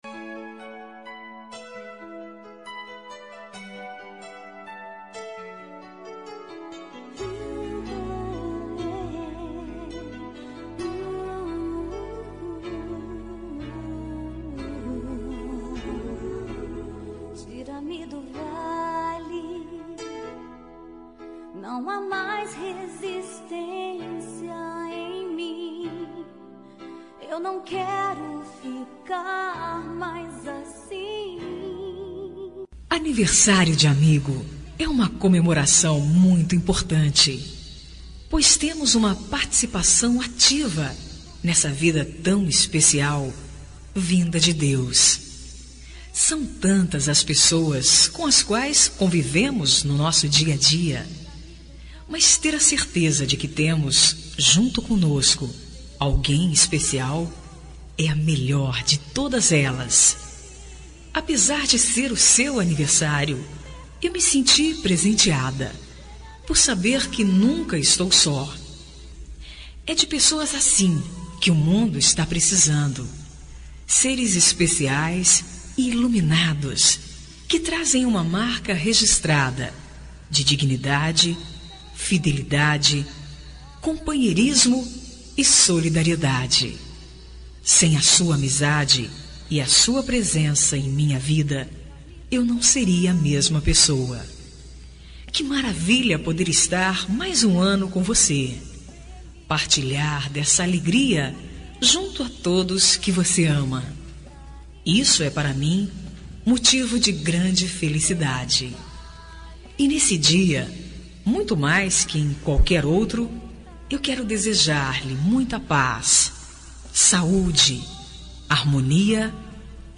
Aniversário de Amigo Gospel – Voz Feminina – Cód: 60244